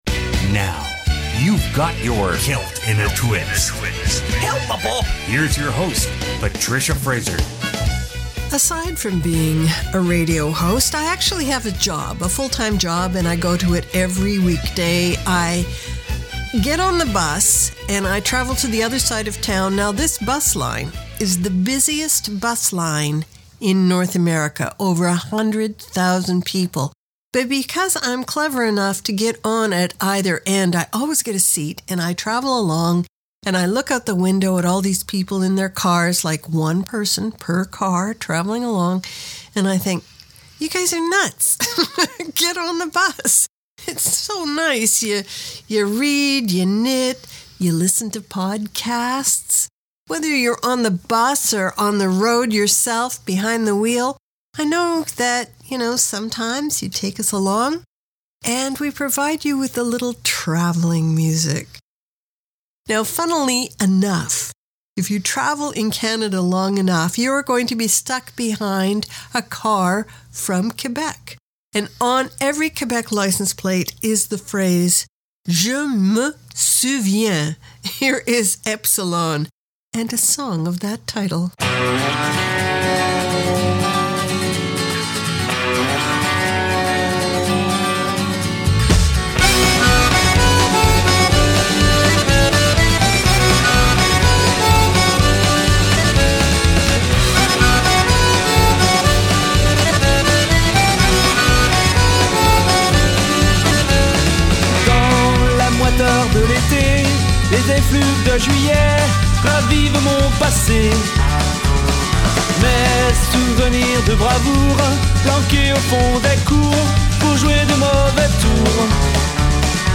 Canada's Contemporary Celtic Radio Hour